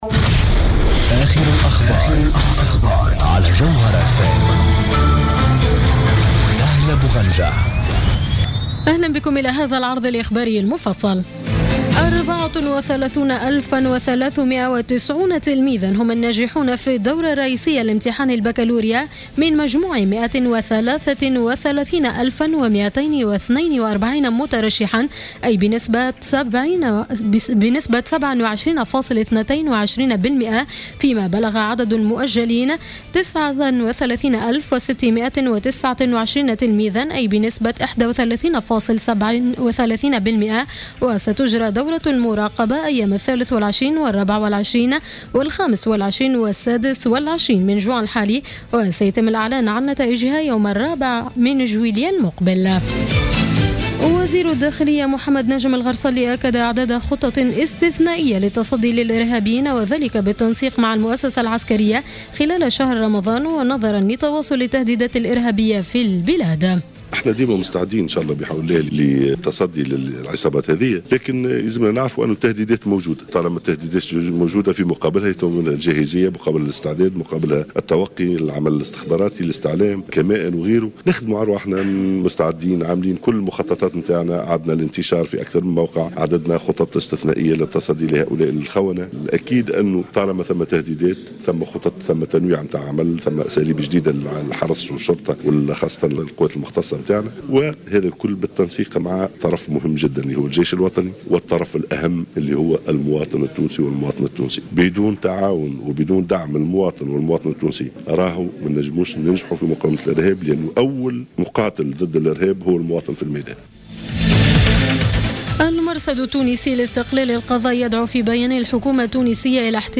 نشرة أخبار منتصف الليل ليوم السبت 20 جوان 2015